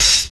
113 HAT.wav